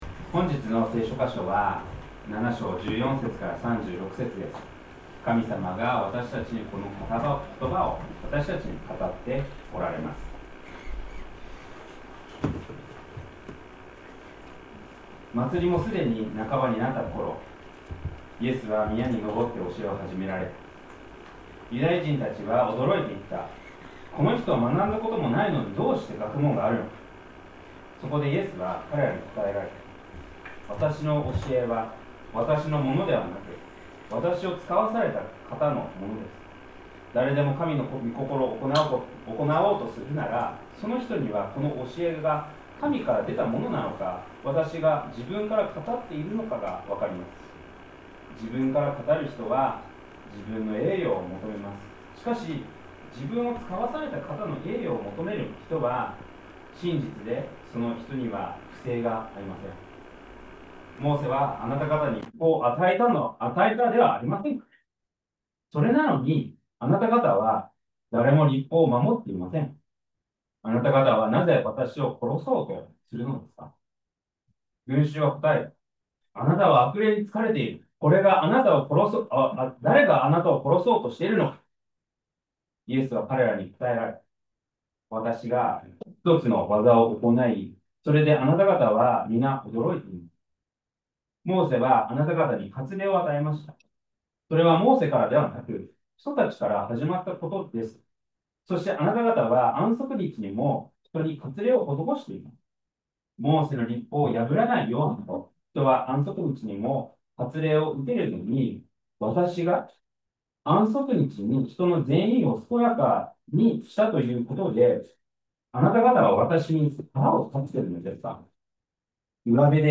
2025年10月26日礼拝 説教 「イエス様を信じない４つの理由」 – 海浜幕張めぐみ教会 – Kaihin Makuhari Grace Church